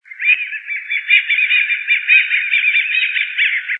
Gavião-carijó (Rupornis magnirostris)
Fase da vida: Adulto
Localidade ou área protegida: Reserva Ecológica Costanera Sur (RECS)
Condição: Selvagem
Certeza: Gravado Vocal